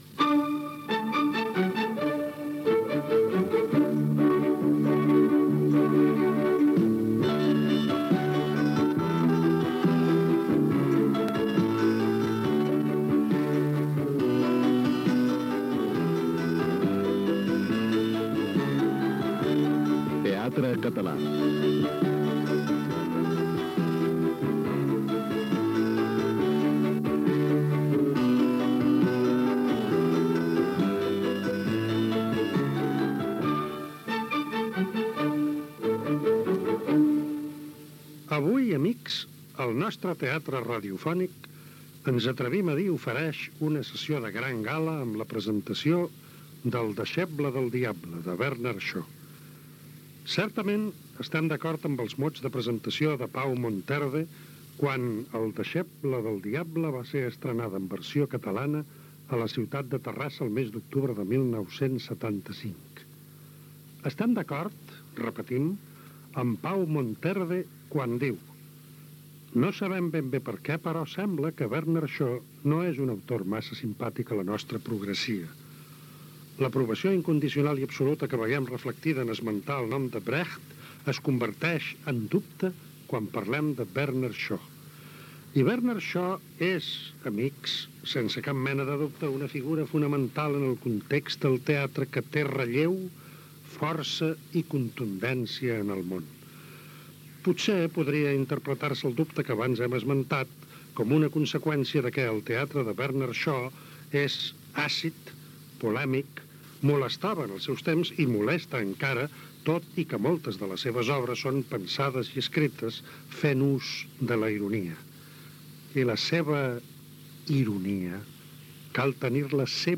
e568f24a16df24d6e4a6a9fcf1daf545ffba5f55.mp3 Títol Ràdio 4 Emissora Ràdio 4 Cadena RNE Titularitat Pública estatal Nom programa Teatre en català Descripció Adaptació radiofònica de l'obra «El deixeble del Diable» de Bernard Shaw.
Diàleg entre la mare, la filla del seu germà i el seu fill que arriba.